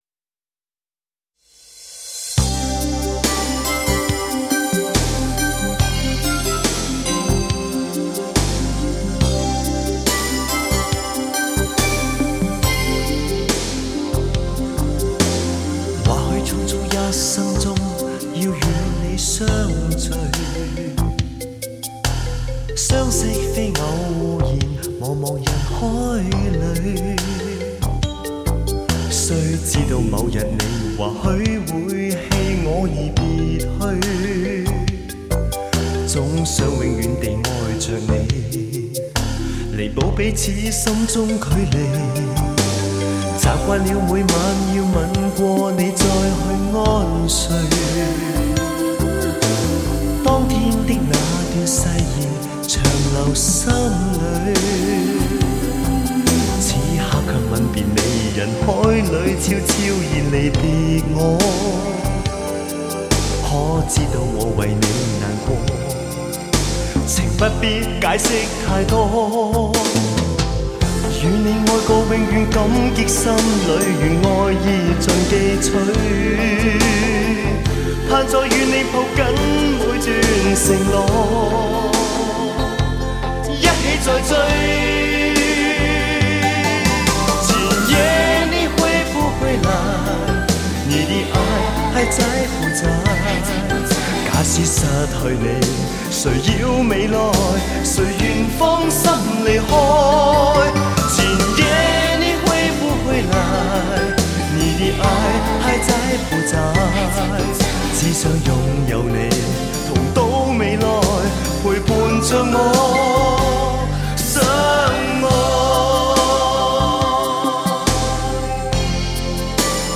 划时代顶级发烧母版处理
音色更接近模拟(Analogue)声效
强劲动态音效中横溢出细致韵味